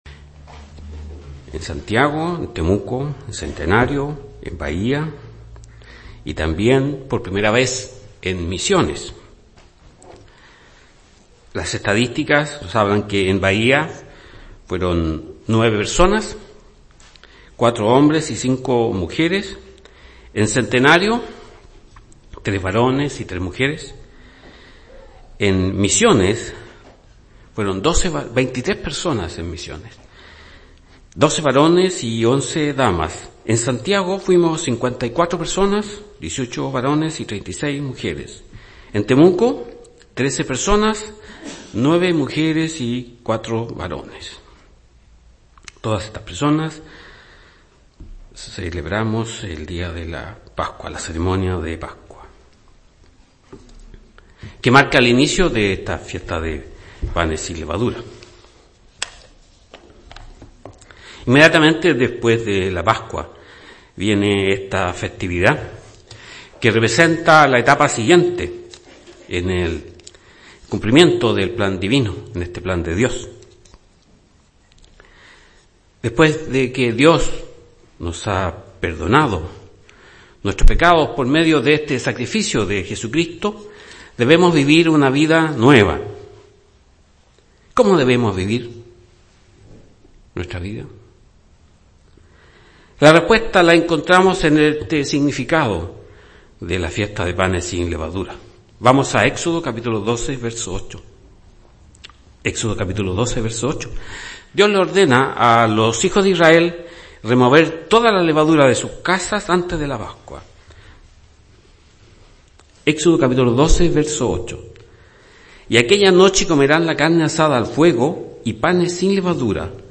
Sermones
Given in Santiago